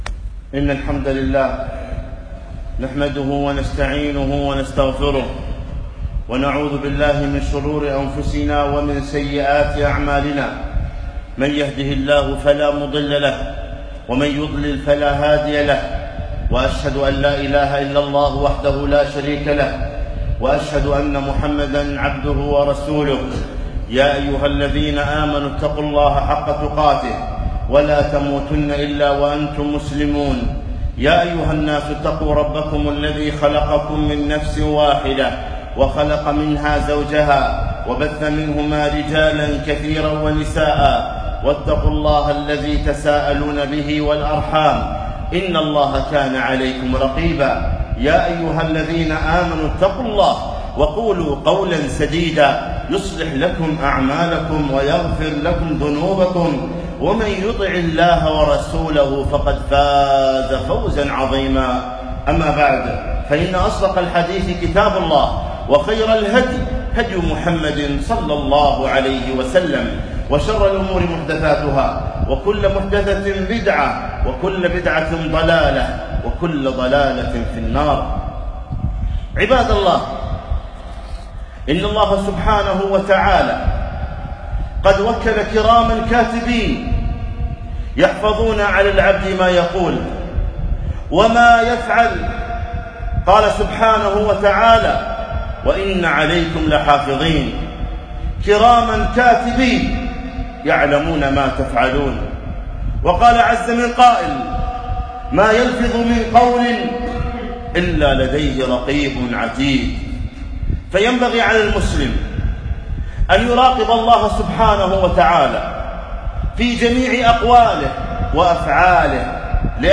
خطبة - أخطاء لفظية شائعة - دروس الكويت
خطبة - أخطاء لفظية شائعة